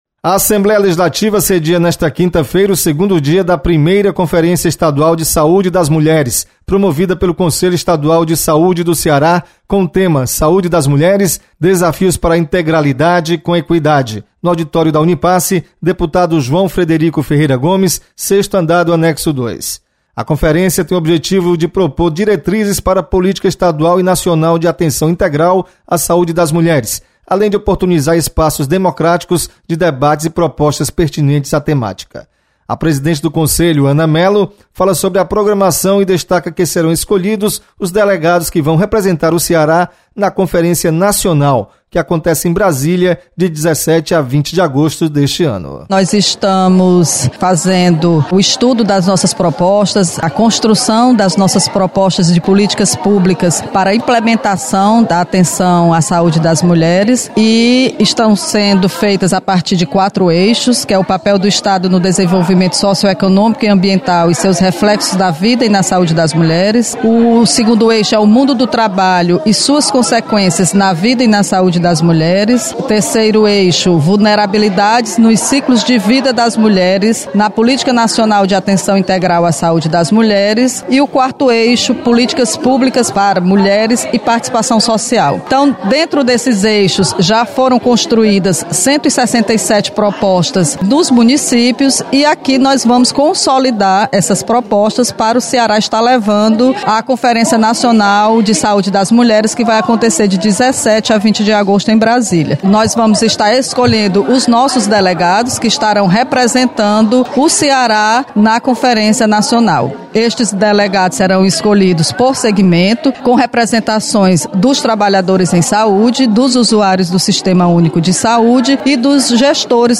Você está aqui: Início Comunicação Rádio FM Assembleia Notícias Saúde